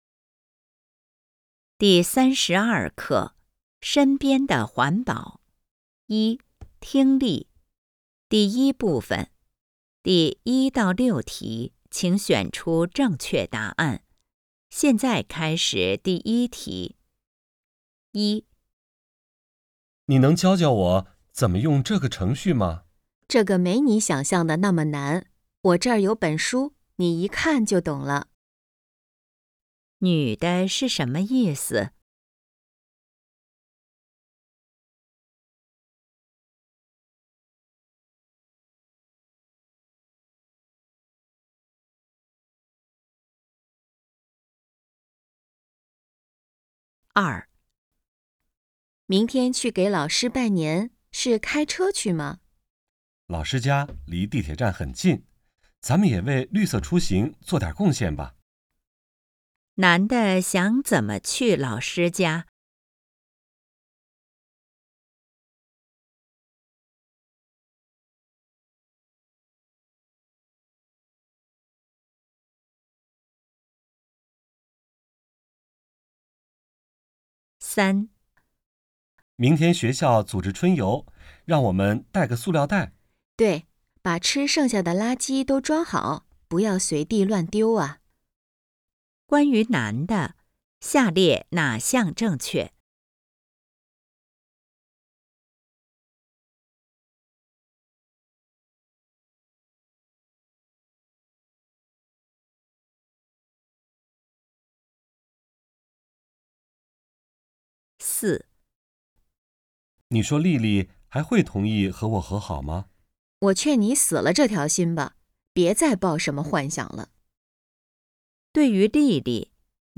一、听力